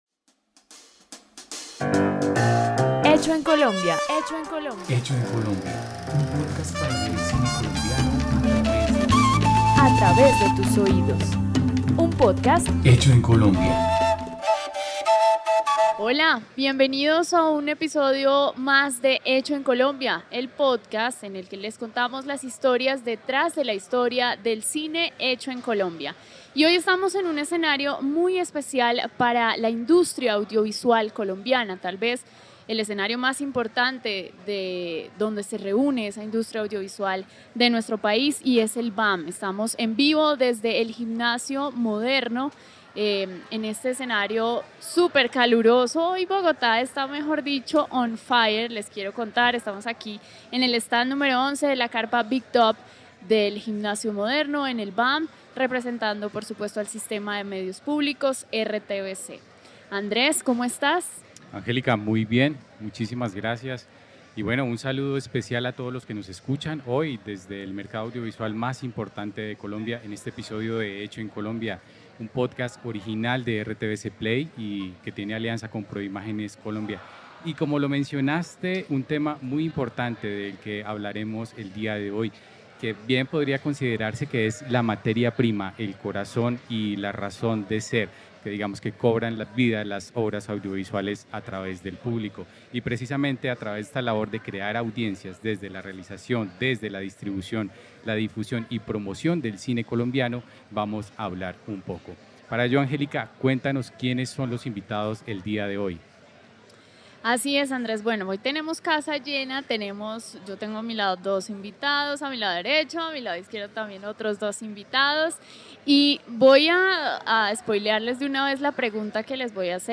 Un episodio especial desde el Bogotá Audiovisual Market (BAM) en el que hablamos con expertos sobre el reto que enfrentan las producciones colombianas para crear audiencias desde los procesos de realización, distribución, difusión y promoción.
podcast en vivo